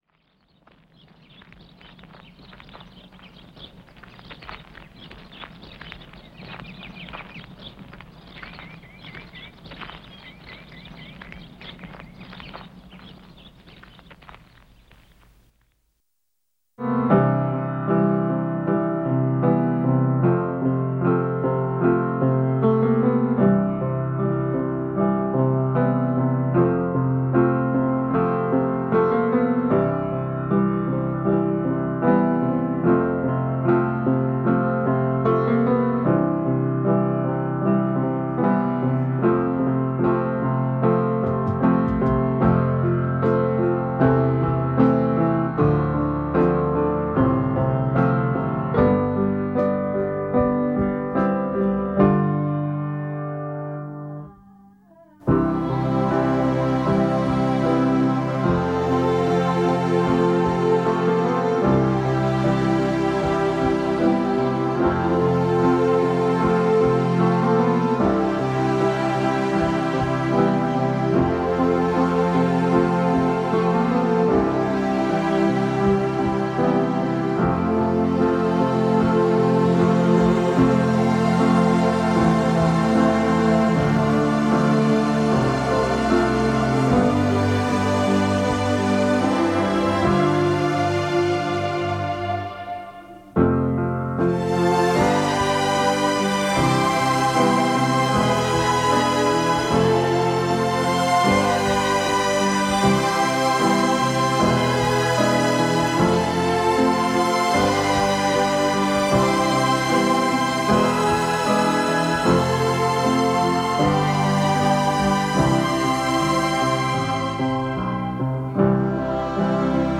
These are my mixes!!!
Just Piano and the Strings turned up in the mix